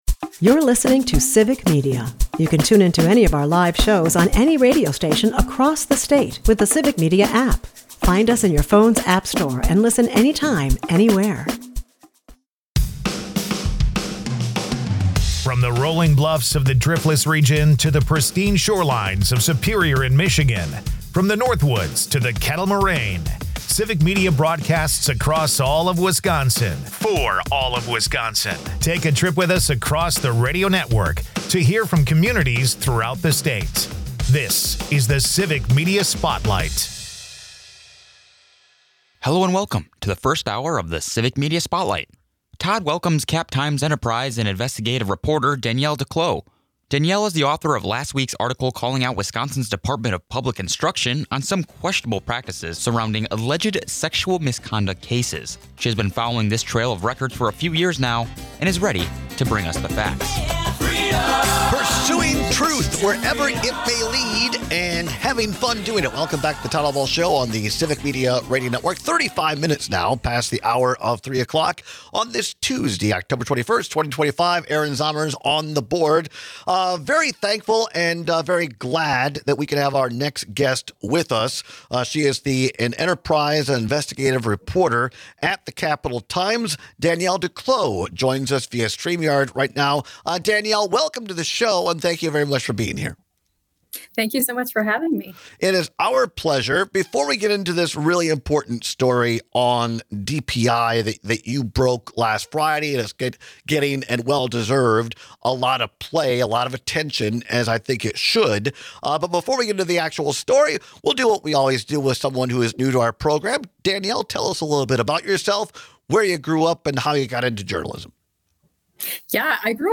In the second segment, we listen in on our Civic Media reporters talk about the No Kings Protest that happened last weekend.